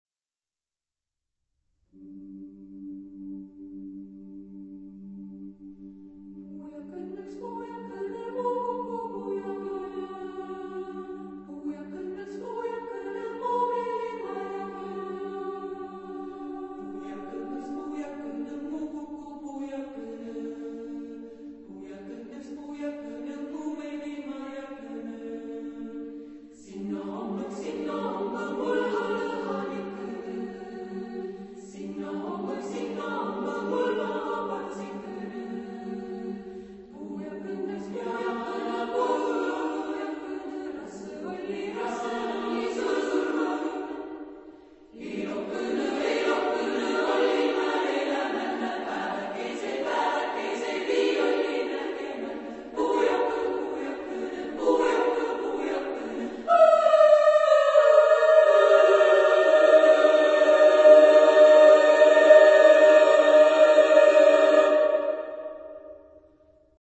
Genre-Style-Form: Secular ; Traditional ; Vocal piece
Type of Choir: SSAA (div)  (4 women voices )
Tonality: various